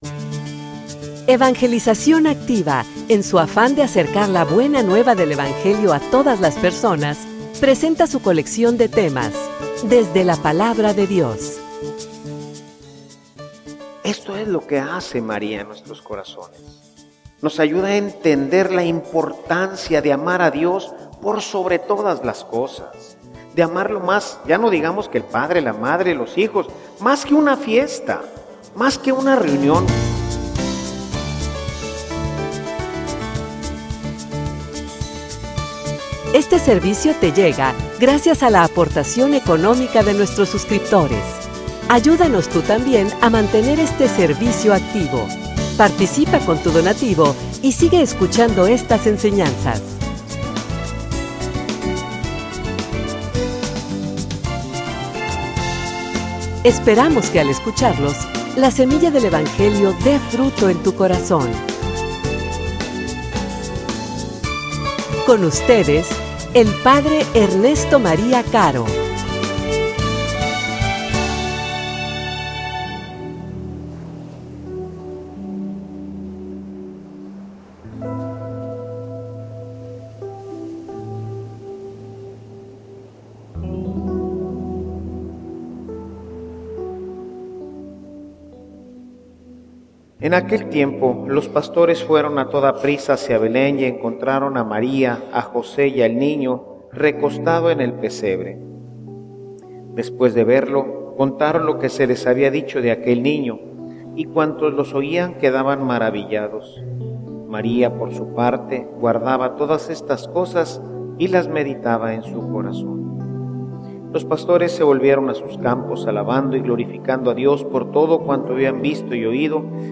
homilia_Llevatela_a_tu_casa.mp3